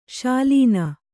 ♪ śalīna